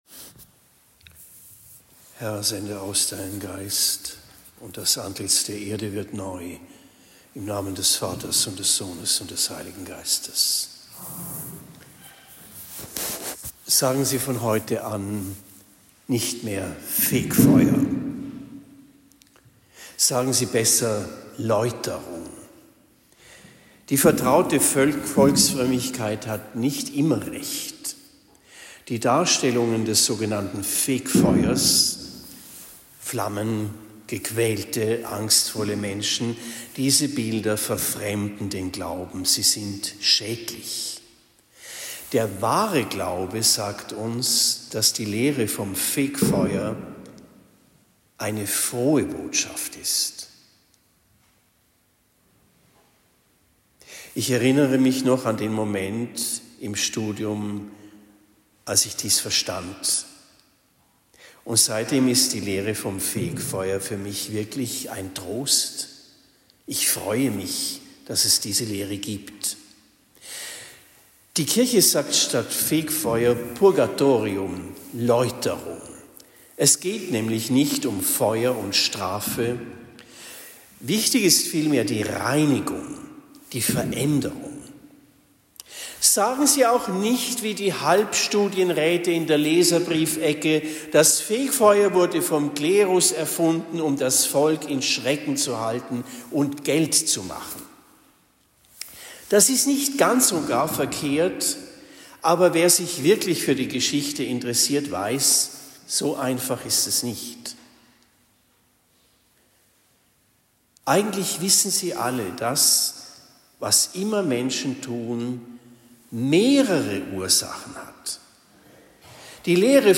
Allerseelen 2025 Predigt in Steinfeld am 02. November 2025